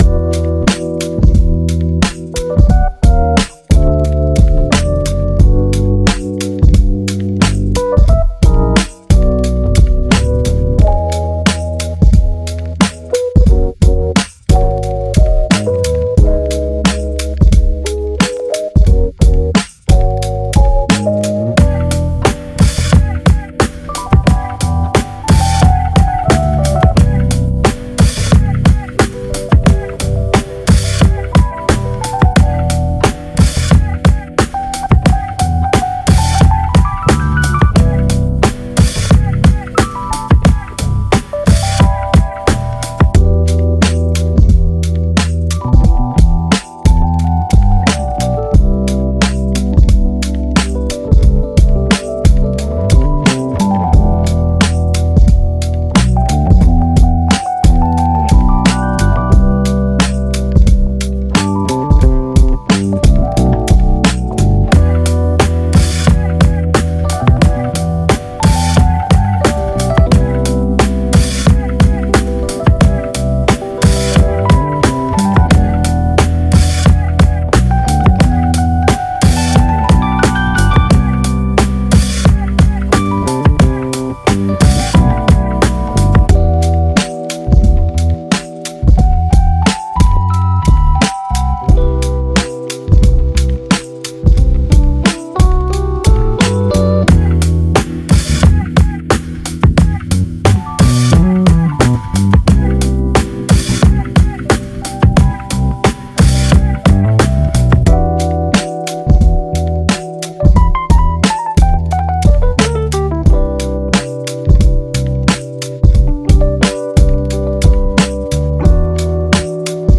Genre:Lo-Fi Hip Hop
ピアノループを補完するのは、同じく89 BPMで収録された51のベースループです。
49 Electric Piano Loops
51 Bass Loops